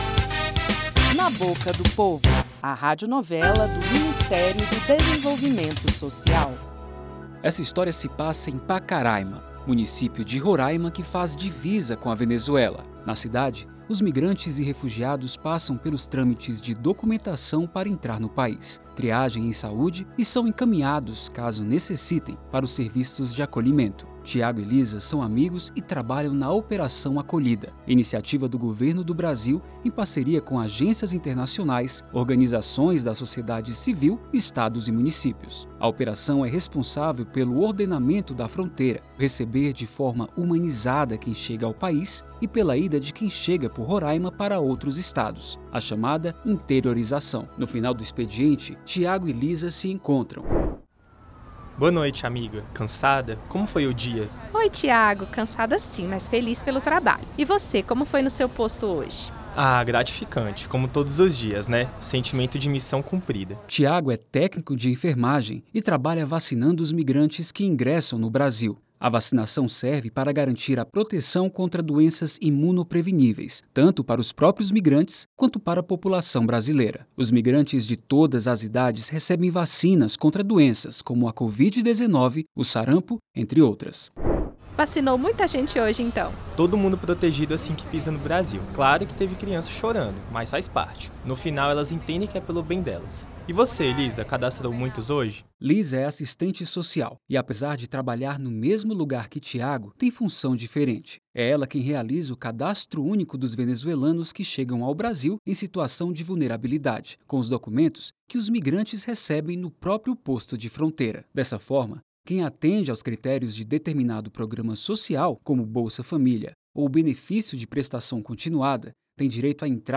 Na Boca do Povo - Radionovela Na Boca do Povo: entenda as condicionalidades do Bolsa Família Neste segundo episódio, a radionovela do MDS explica as condicionalidade de saúde e educação do Bolsa Família.